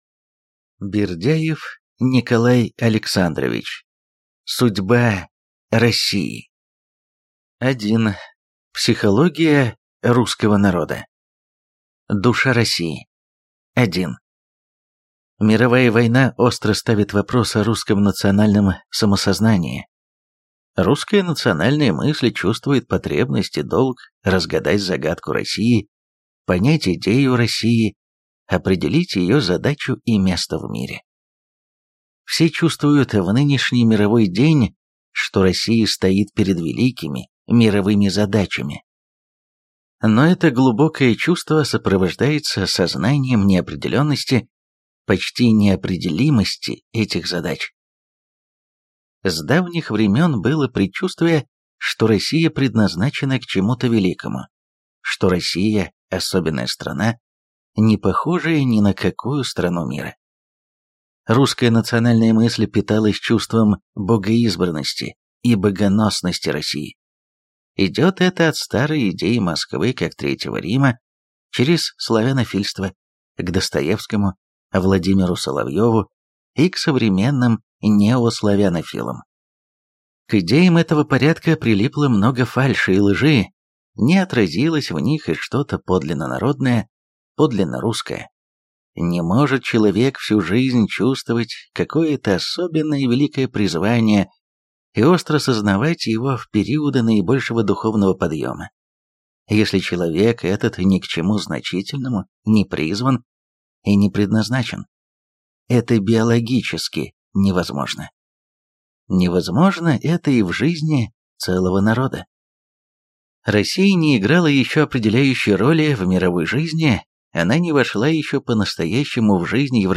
Аудиокнига Судьба России | Библиотека аудиокниг